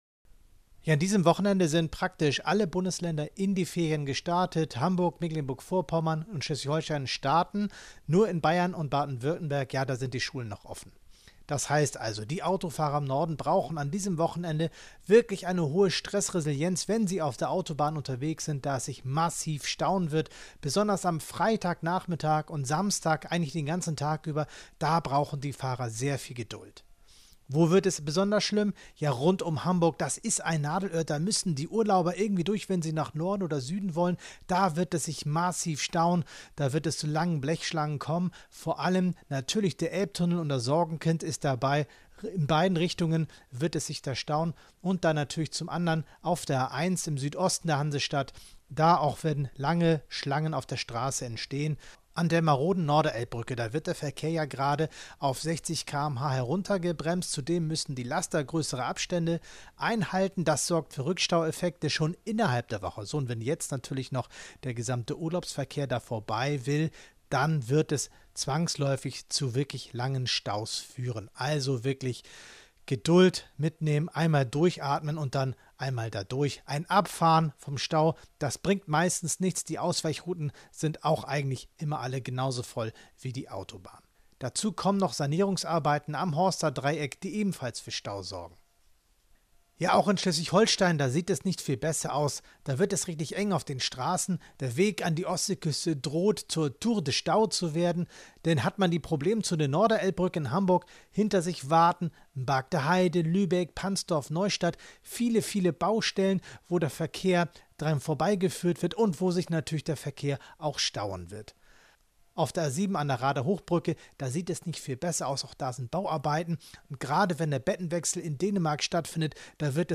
Audiopaket zur Stauprognose.